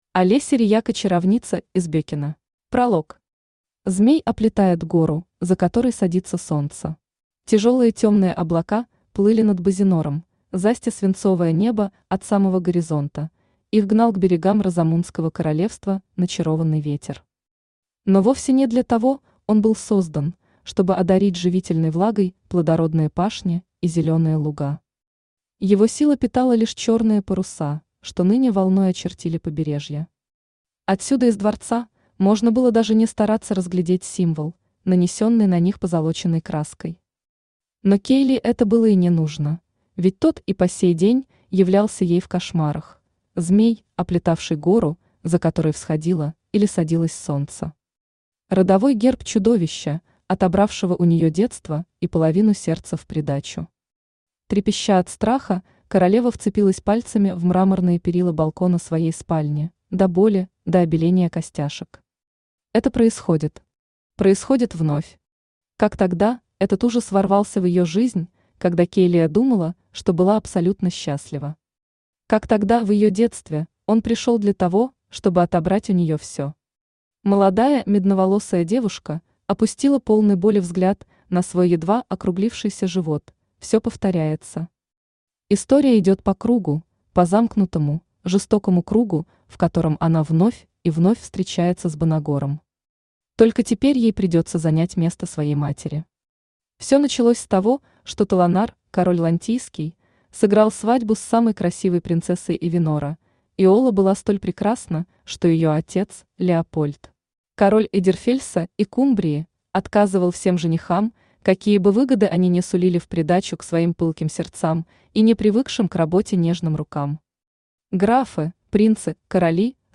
Аудиокнига Чаровница из Беккена | Библиотека аудиокниг
Aудиокнига Чаровница из Беккена Автор Олеся Рияко Читает аудиокнигу Авточтец ЛитРес.